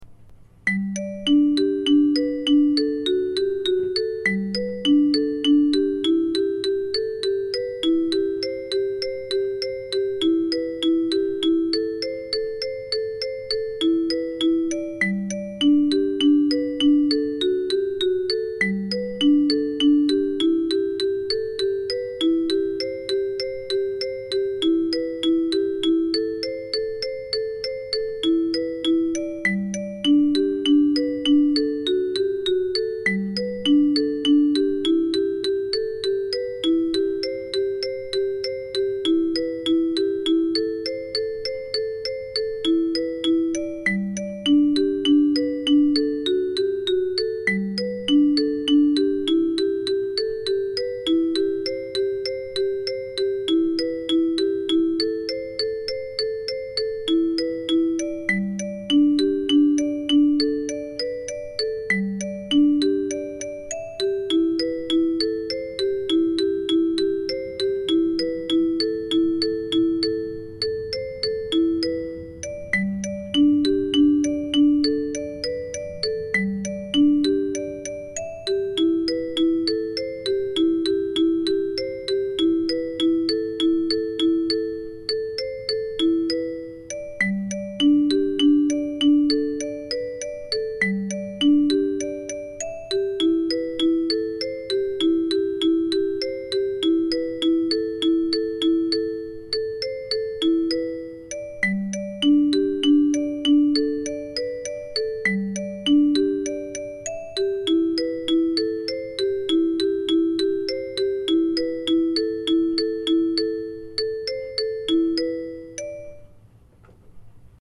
This traditional mbira song transfers well to karimba
Download MP3 of Cycles 3 & 4 at Slow Tempo